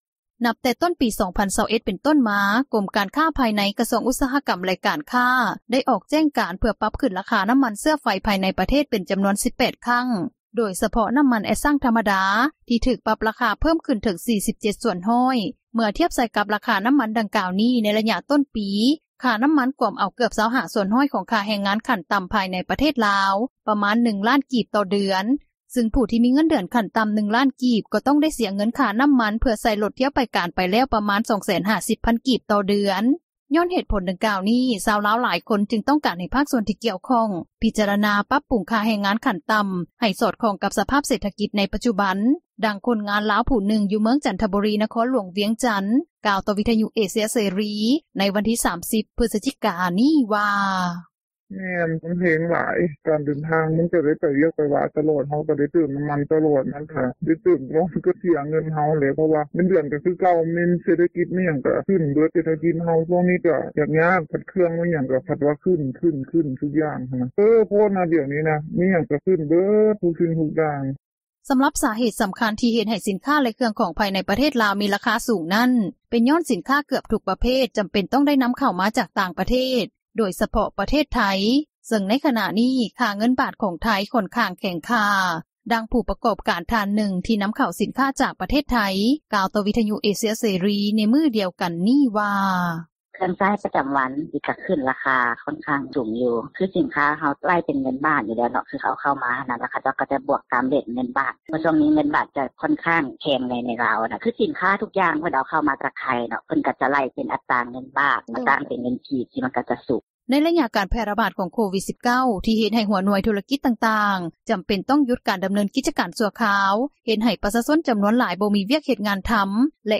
ຍ້ອນເຫດຜົນດັ່ງກ່າວນີ້ ຊາວລາວຫຼາຍຄົນ ຈຶ່ງຕ້ອງການໃຫ້ພາກສ່ວນທີ່ກ່ຽວຂ້ອງ ພິຈາຣະນາ ປັບປຸງຄ່າແຮງງານຂັ້ນຕໍ່າ ໃຫ້ສອດຄ່ອງກັບສະພາບການຂອງເສຖກິຈໃນປັດຈຸບັນ, ດັ່ງຄົນງານລາວ ຜູ້ນຶ່ງ ຢູ່ເມືອງຈັນທະບູລີ ນະຄອນຫຼວງວຽງຈັນ ກ່າວຕໍ່ວິທຍຸເອເຊັຽເສຣີ ໃນວັນທີ່ 30 ພຶສຈິການີ້ວ່າ: